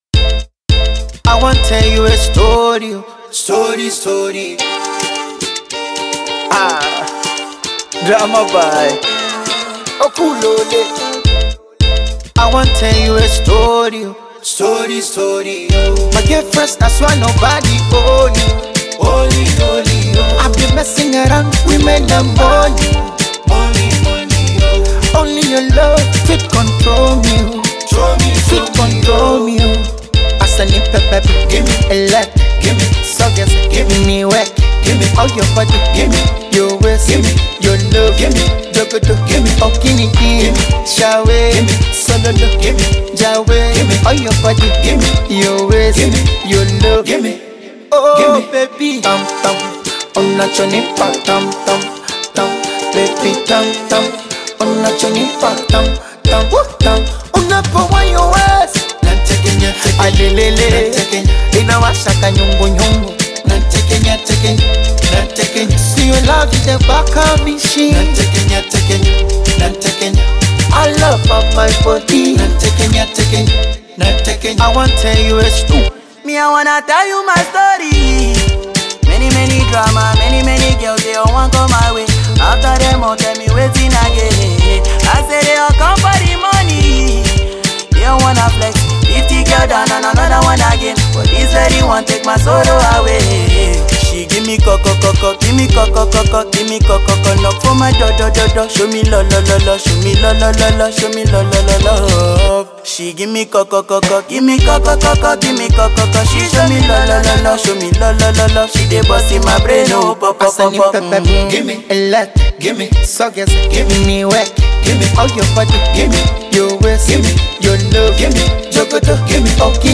Afro-fussed song